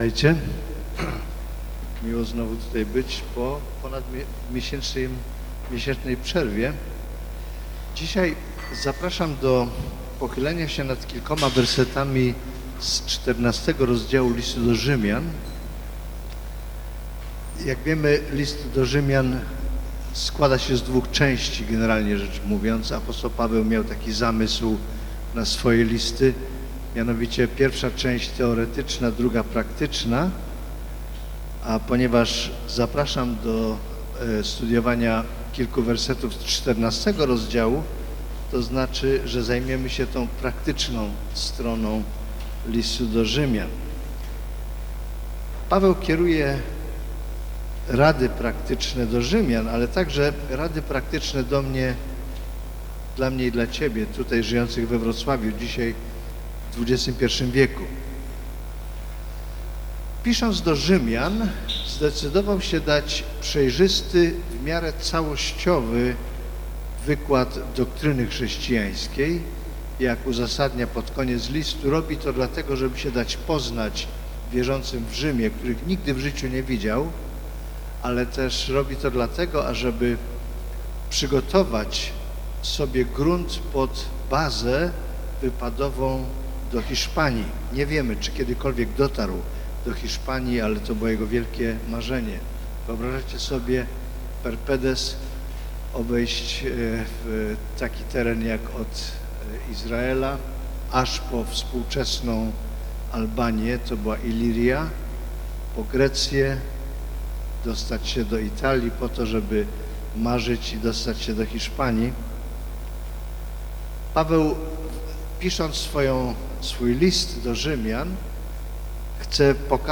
Rz 14 Rodzaj Usługi: Nauczanie niedzielne Tematy: Legalizm , Osądzanie , Wolność « Lustro w lustrze Ew.